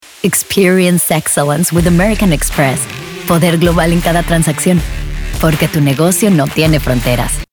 Commercial
Authoritative - Sophisticated